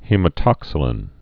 (hēmə-tŏksə-lĭn)